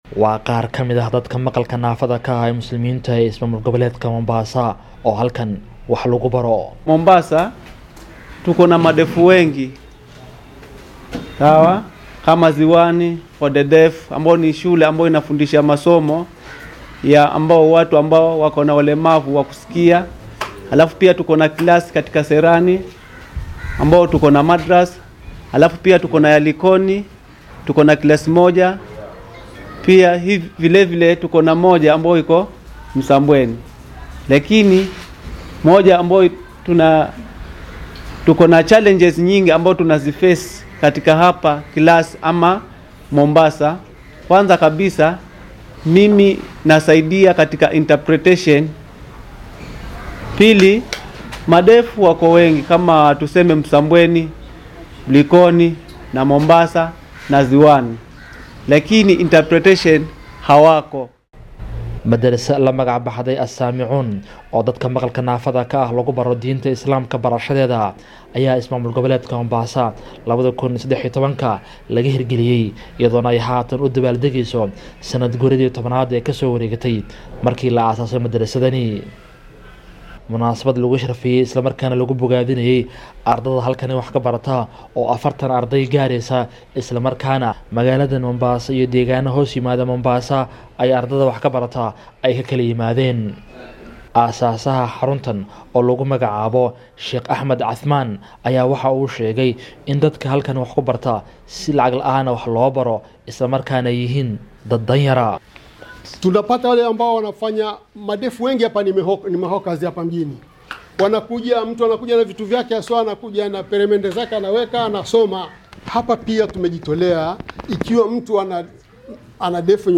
DHAGEYSO:Munaasabad lagu abaal mariyay ardayda madarasada naafada ee Mombasa